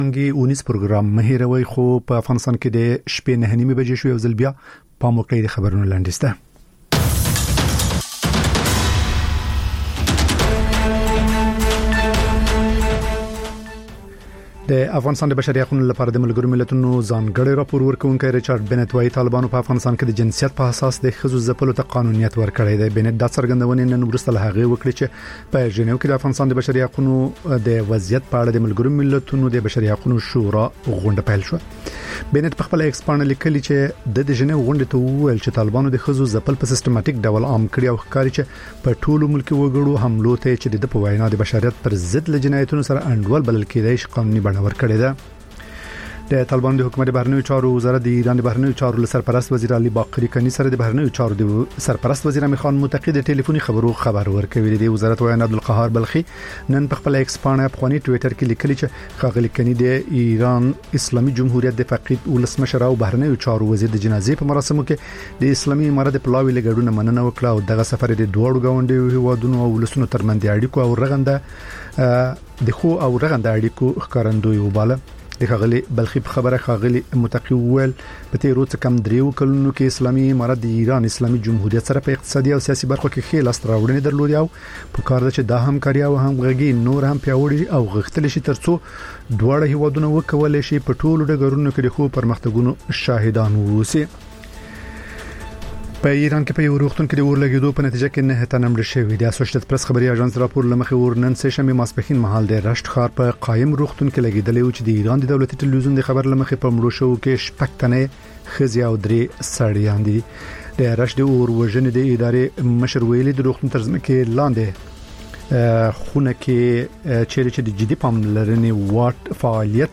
لنډ خبرونه - تاندې څانګې (تکرار)